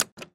Звуки лифта
Звук прибывшего лифта, динь и распахивание дверей